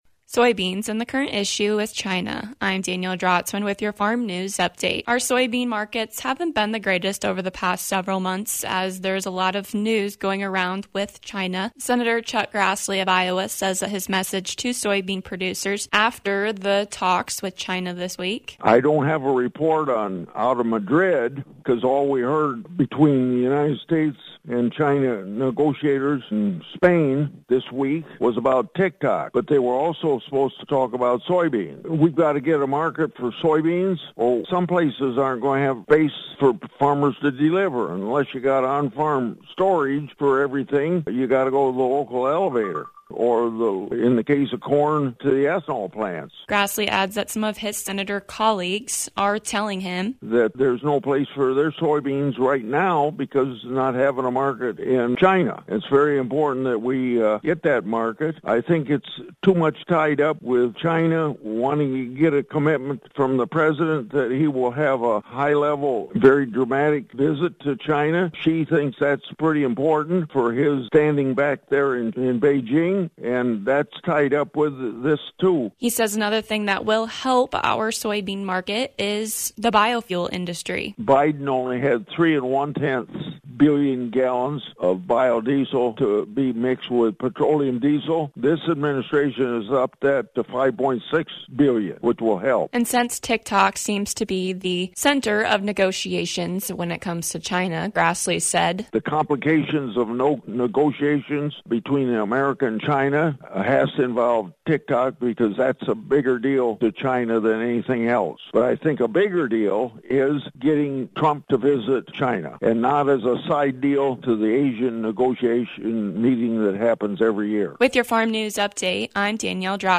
Senator Grassley gives us an update into the recent negotiations with China on Soybeans & Tik Tok.